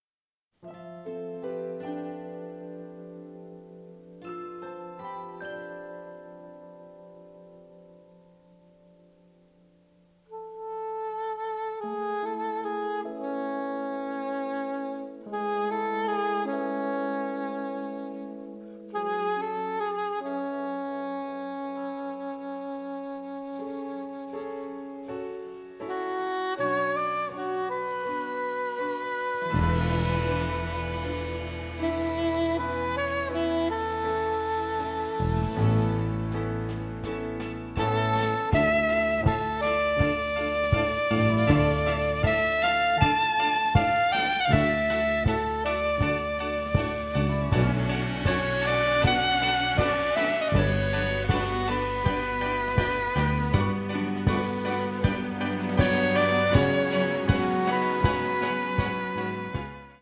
With sax improvisation) 33